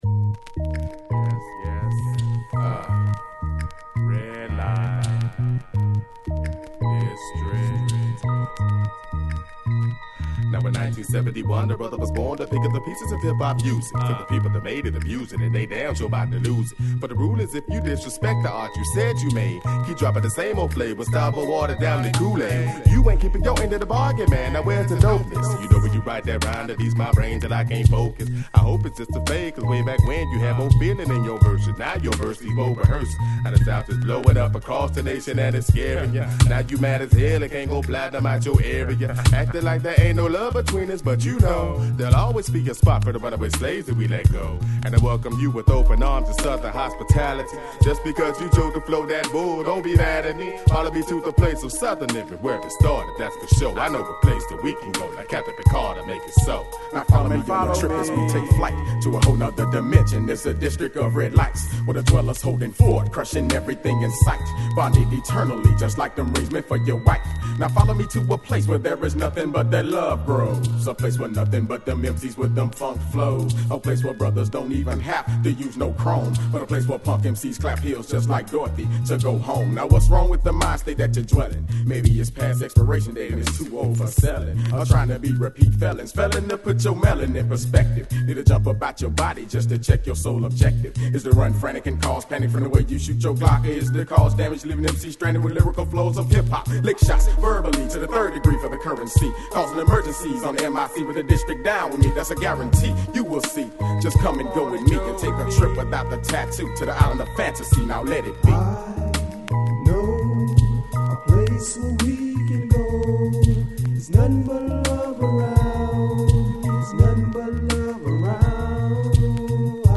-- Alabama���Υ��롼�פˤ��ɥޥ��ʡ�Mid 90's Underground Hip Hop!!